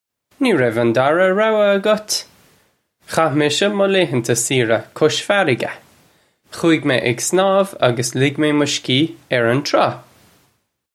Pronunciation for how to say
Nee rev un darra row-a uggut! Khah misha muh lay-hunta seera kush farriga - khoo-ig may ig snawv uggus lig may muh shkee urr un traw.
This is an approximate phonetic pronunciation of the phrase.